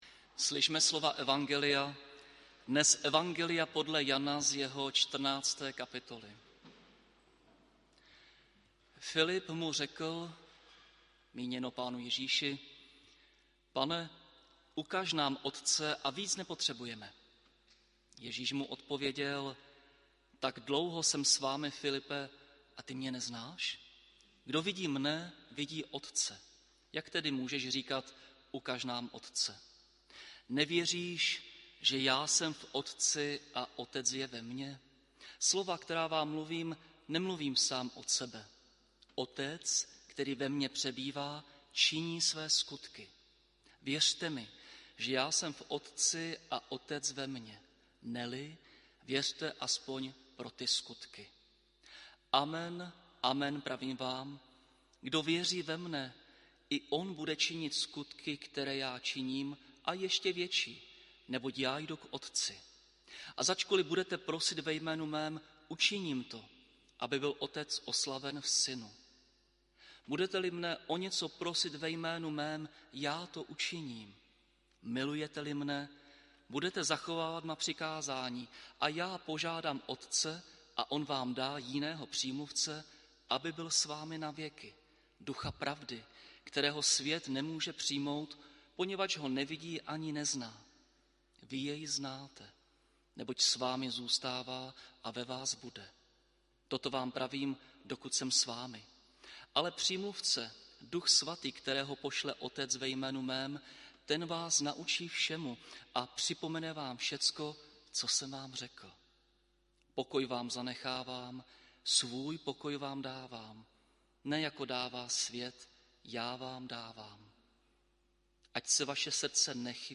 Bohoslužby byly společné s kazatelskou stanicí v Nýřanech.
audio kázání na text ze 14. kapitoly Janova evangelia zde